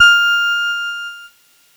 Cheese Note 23-F4.wav